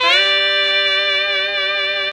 LONG HARM.wav